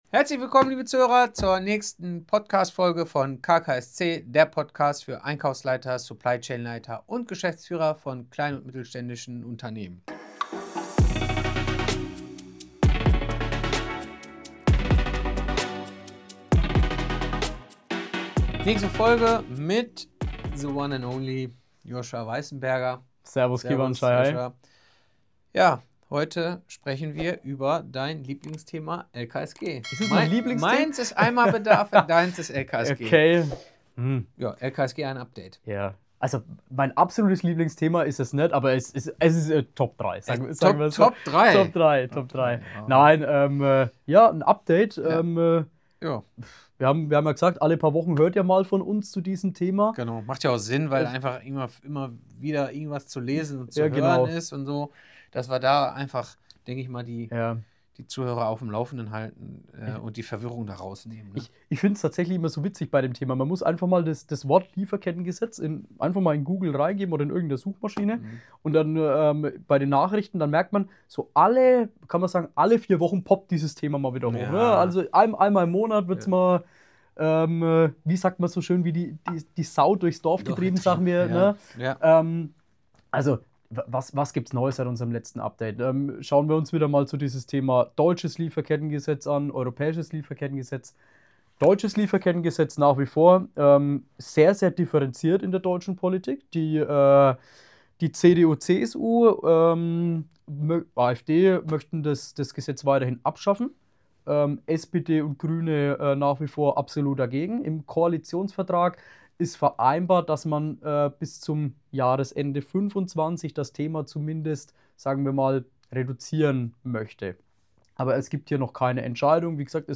Zu Gast ist der Experte